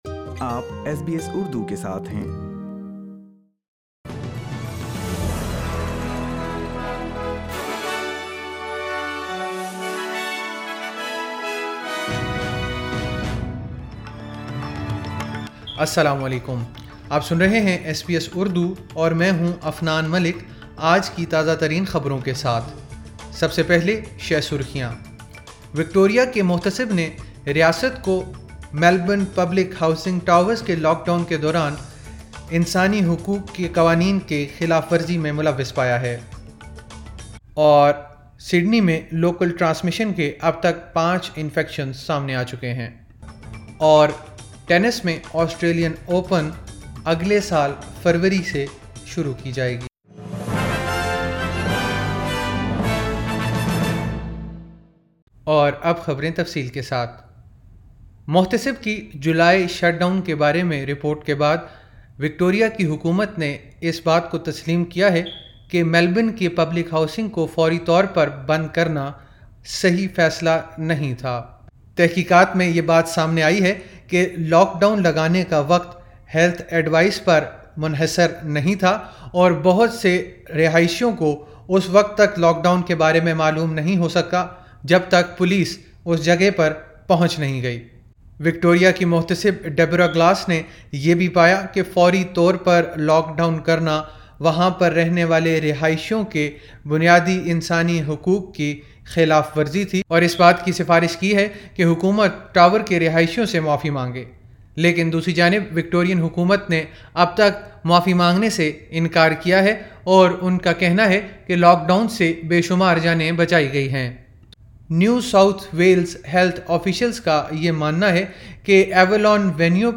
ایس بی ایس اردو خبریں 17 دسمبر 2020